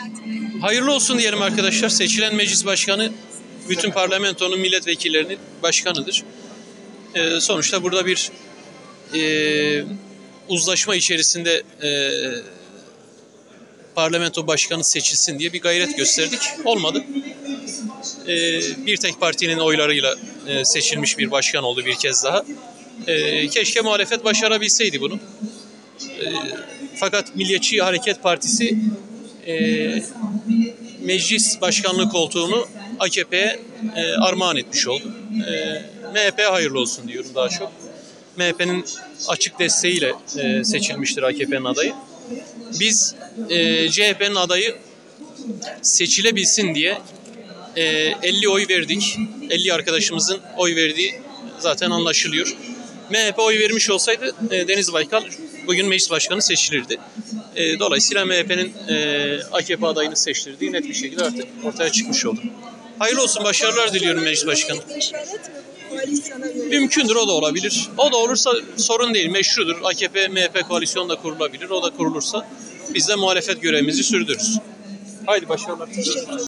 Selahattin Demirtaş'ın Açıklamaları